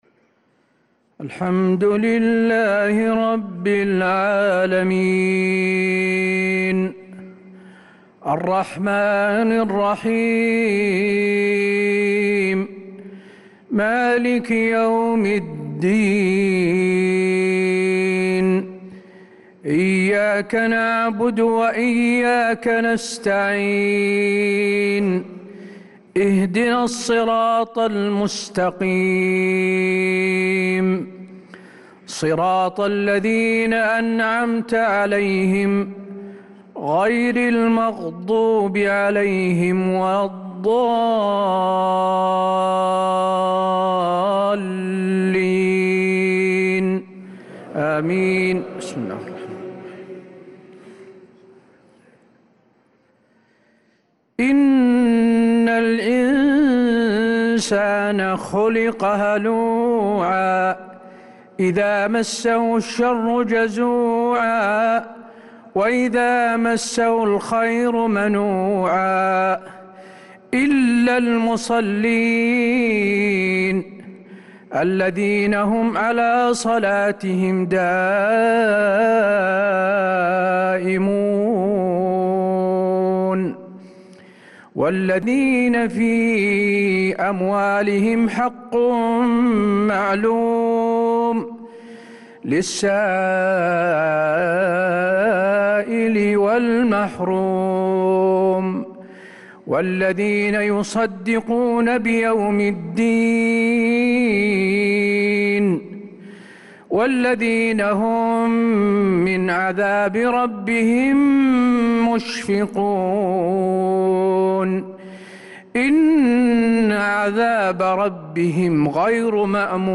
صلاة العشاء للقارئ حسين آل الشيخ 12 ذو القعدة 1445 هـ
تِلَاوَات الْحَرَمَيْن .